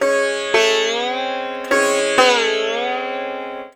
SITAR LINE16.wav